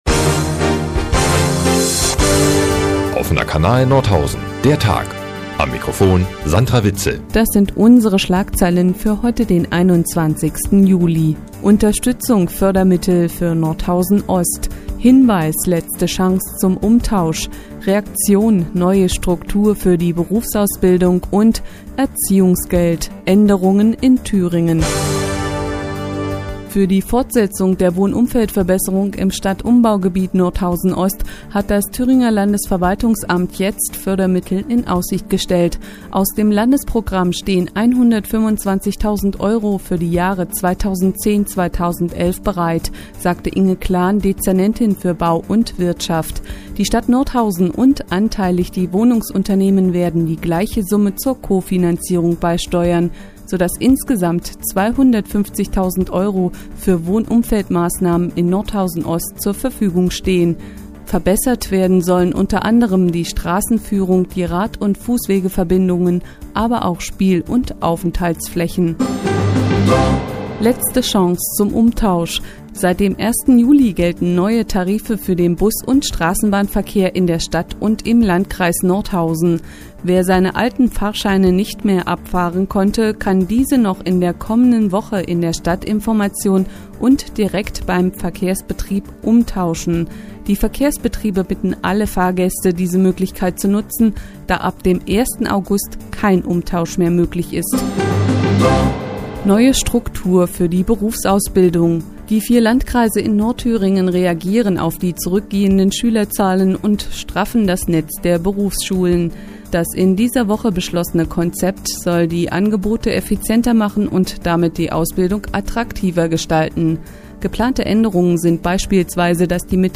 21.07.2010, 15:15 Uhr : Seit Jahren kooperieren die nnz und der Offene Kanal Nordhausen. Die tägliche Nachrichtensendung des OKN ist auch in der nnz zu hören.